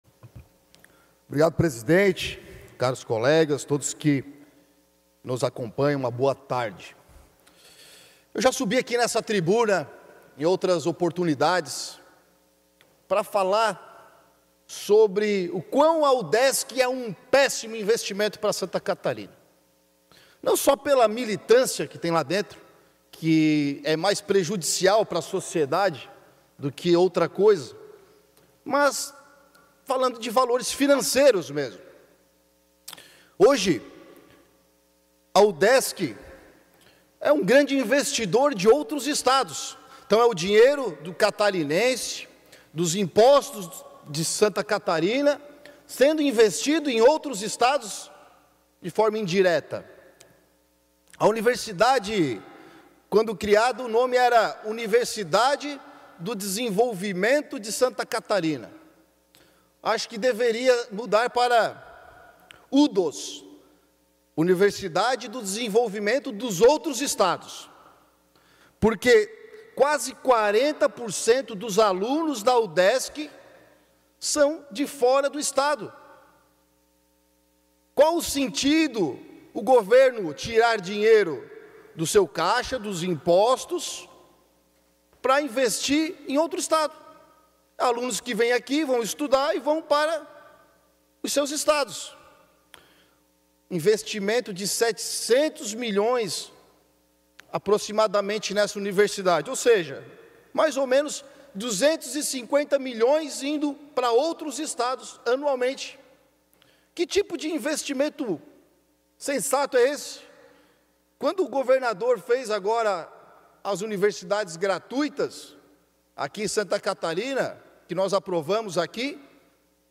Pronunciamentos da sessão ordinária desta quarta-feira (11)